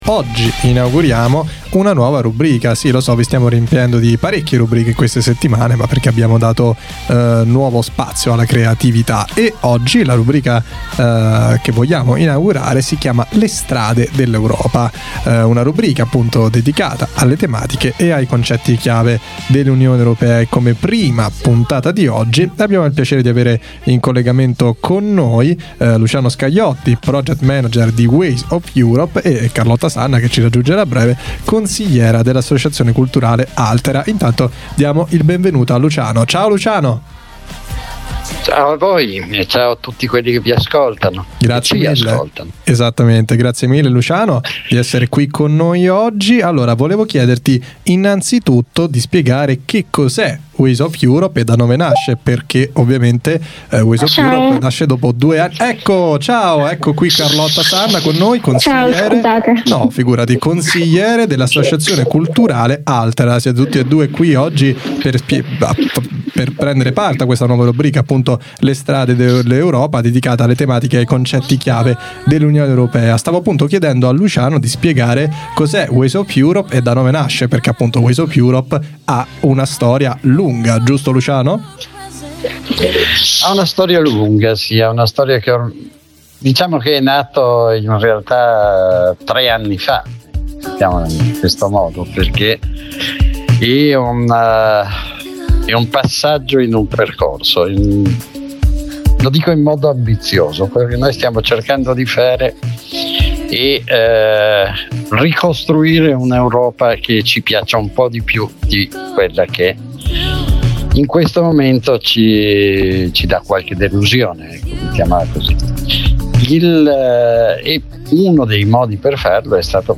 Rethinking Borders – A conversation